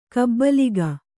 ♪ kabbaliga